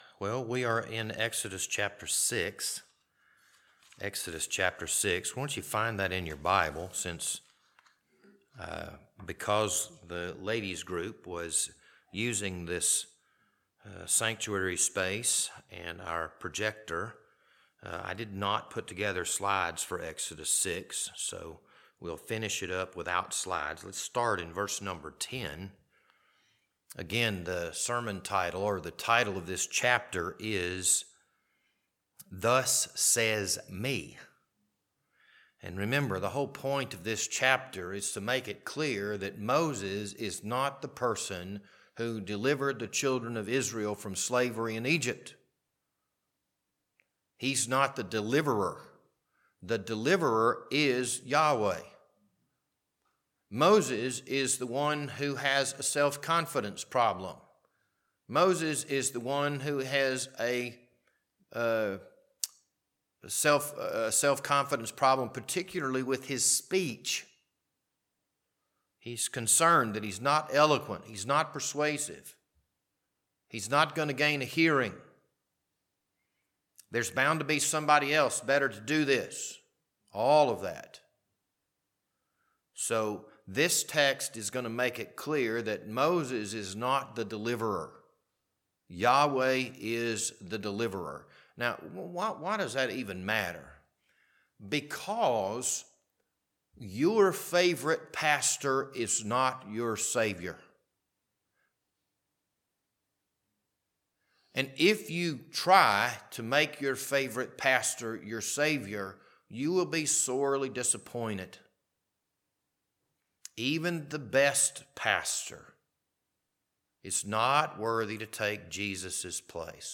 This Wednesday evening Bible study was recorded on April 2nd, 2025.